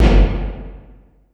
59 REV-BD1-R.wav